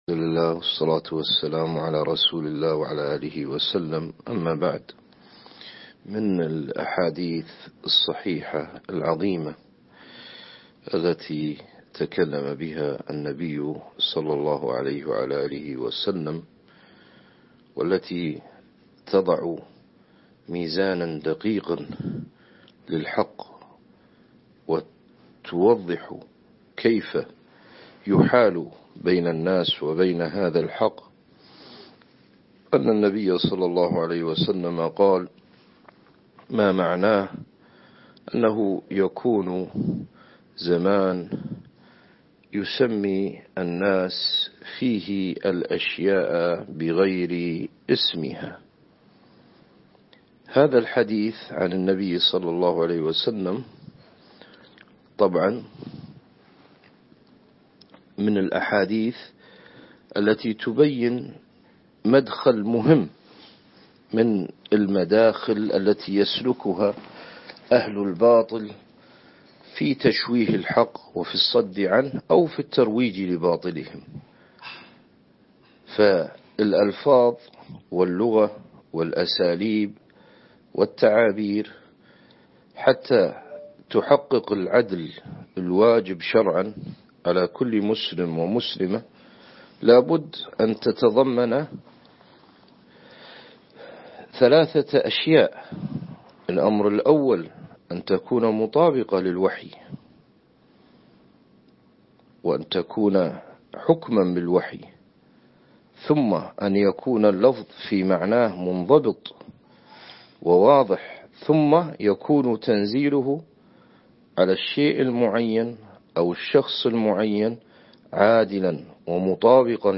كلمات - الدرس الثالث ( لف أهل الأهواء ودورانهم )